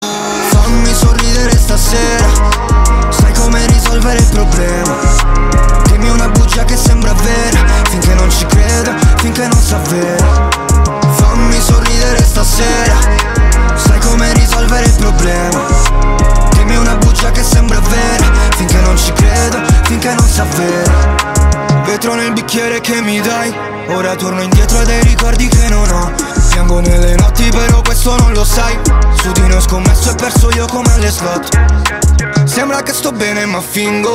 Categoria Hip Hop